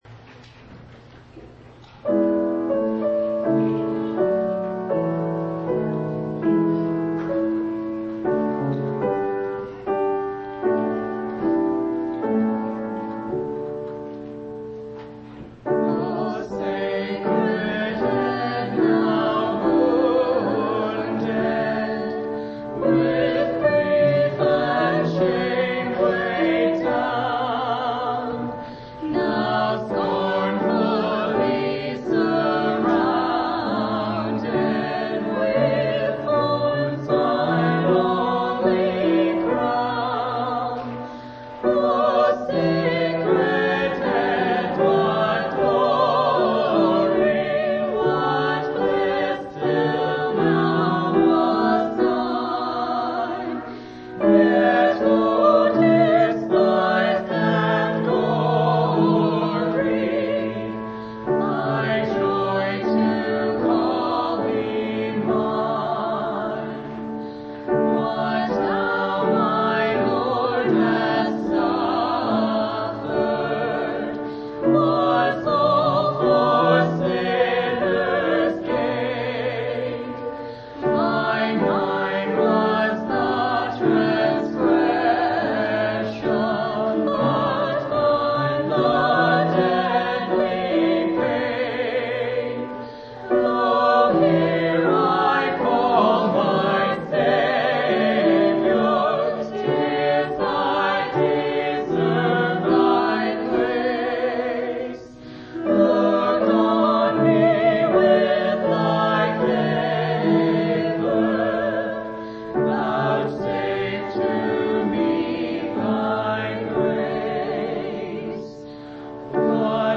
4/9/1997 Location: Temple Lot Local Event